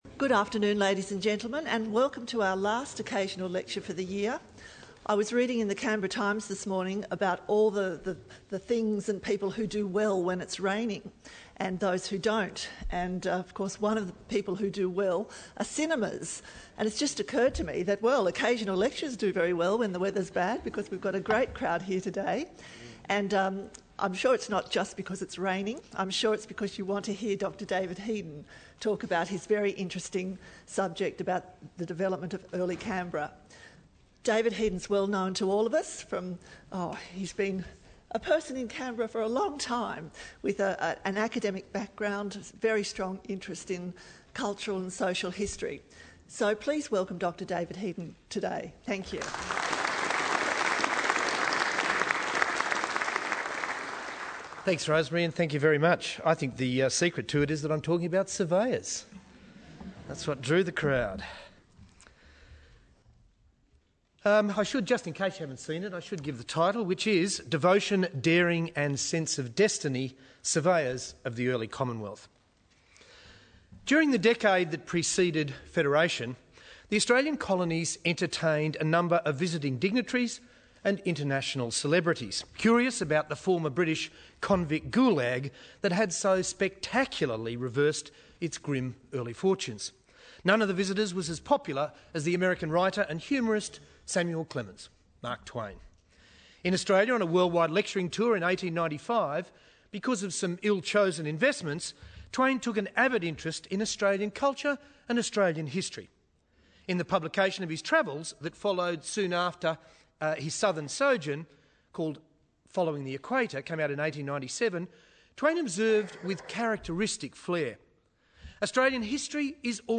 Listen to a recoding of the lecture: Compulsory voting in Australia